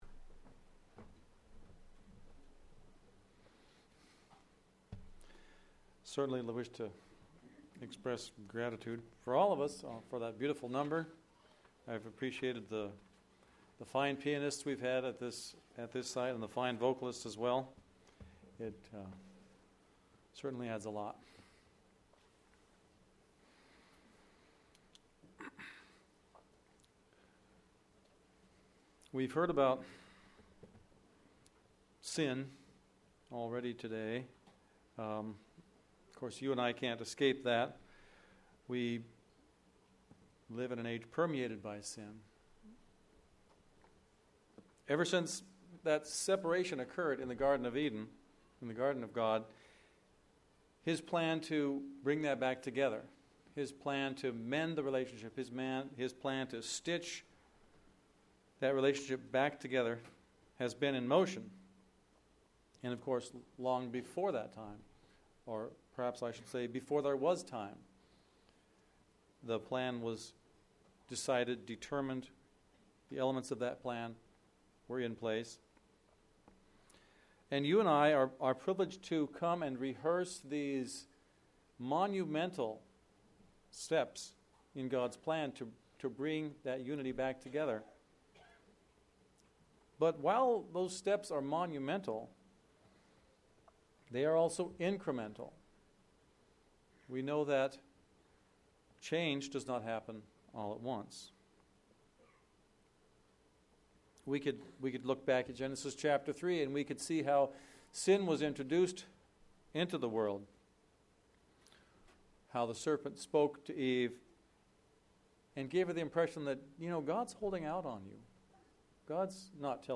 This sermon was given at the Victoria, British Columbia 2016 Feast site.